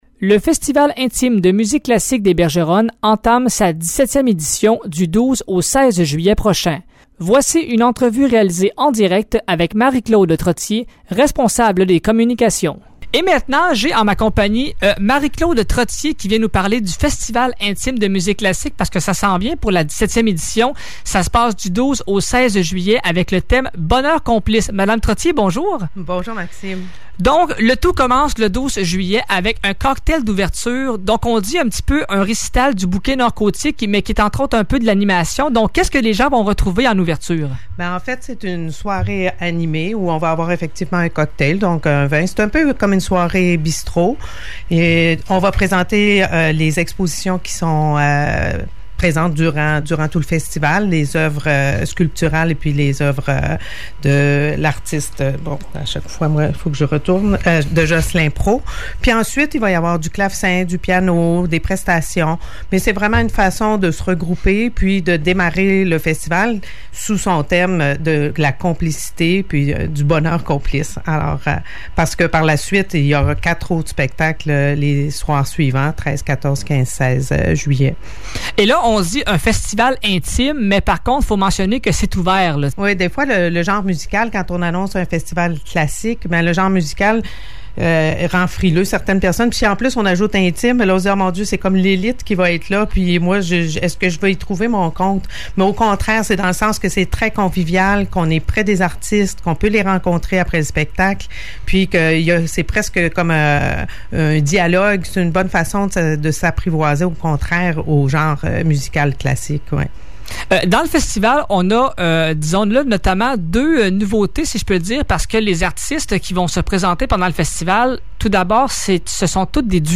Voici une entrevue